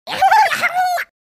Sound Effects
Angry Birds Blue Plush Sound